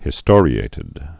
(hĭ-stôrē-ātĭd, -stŏr-)